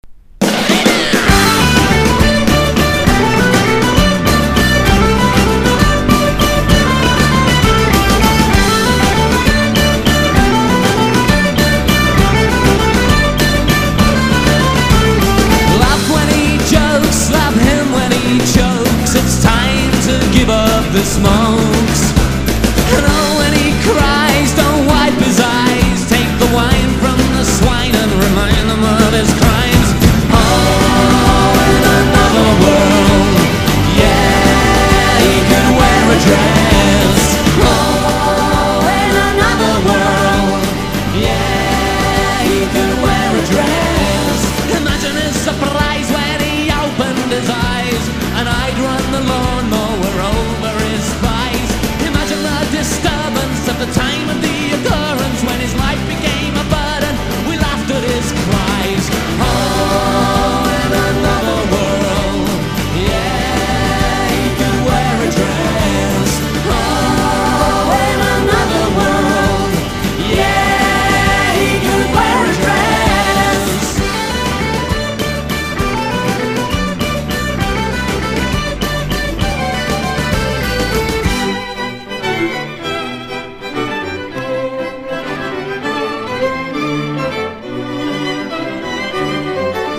1. 90'S ROCK >
NEO ACOUSTIC / GUITAR POP (90-20’s)